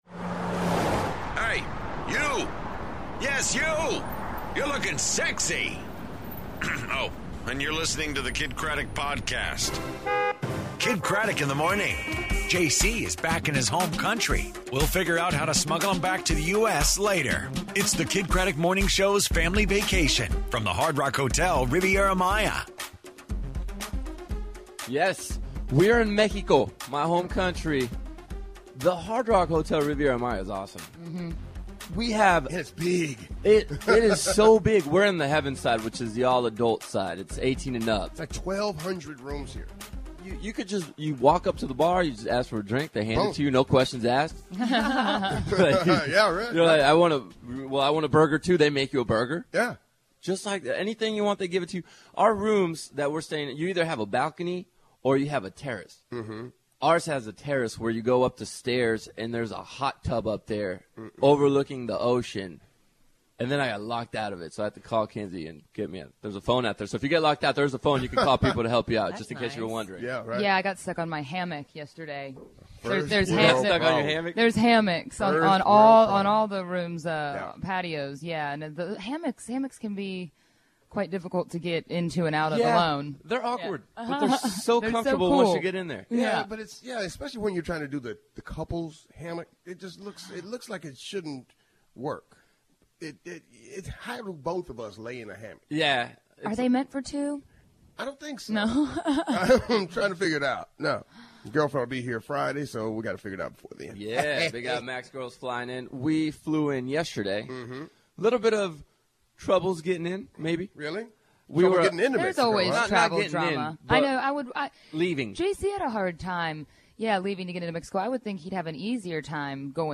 Live From The Hard Rock Hotel In Riviera Maya!